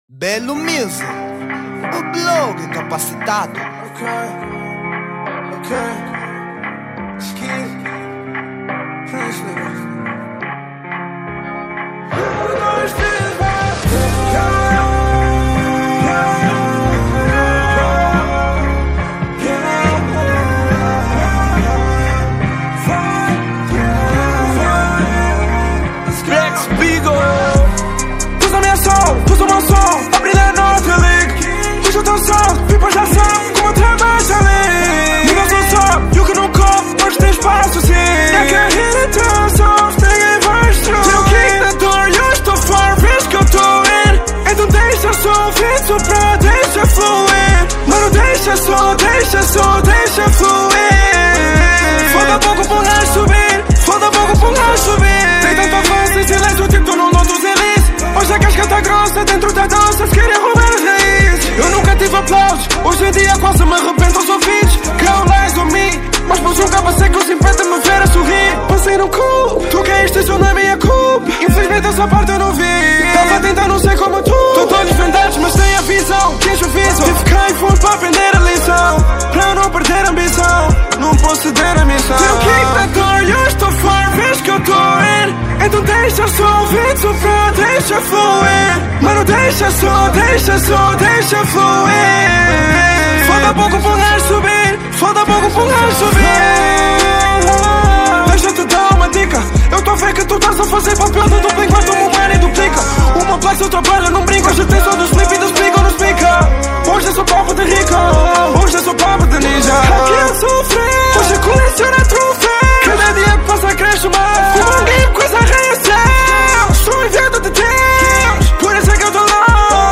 Género : Trap